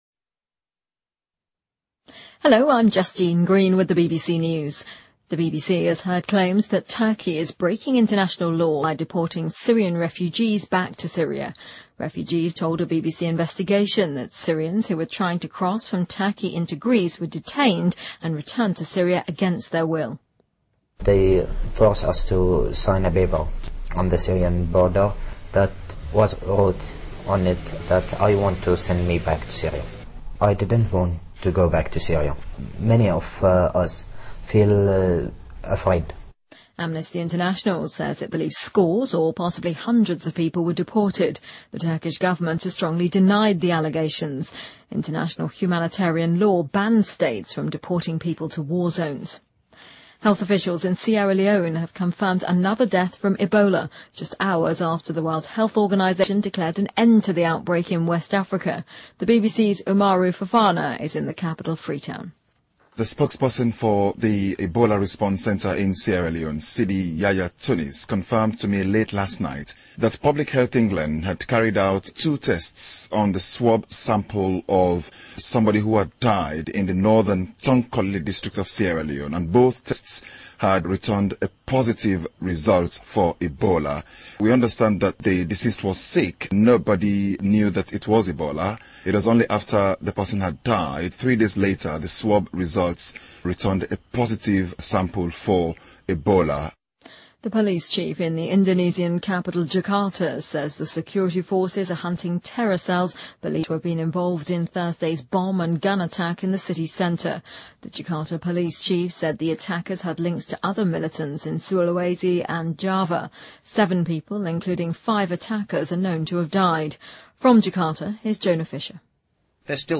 BBC news,印尼首都雅加达发生爆炸枪击案